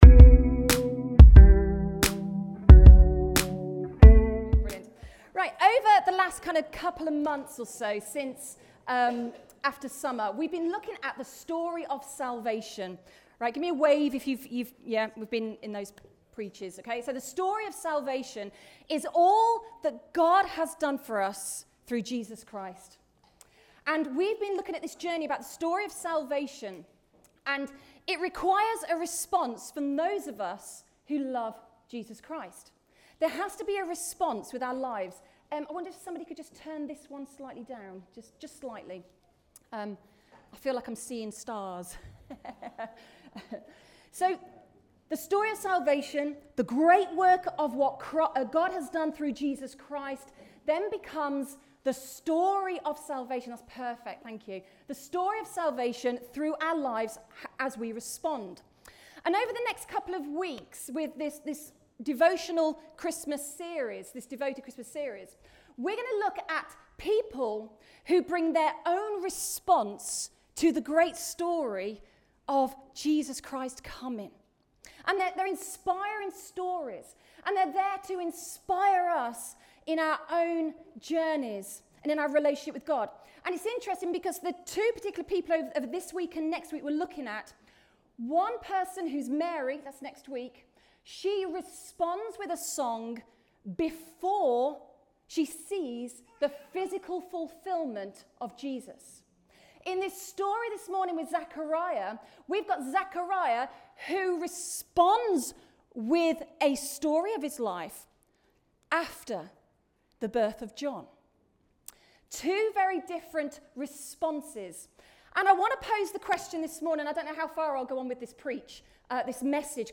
Rediscover Church Newton Abbot | Sunday Messages Devoted Christmas Series: Part 1 | When God Appears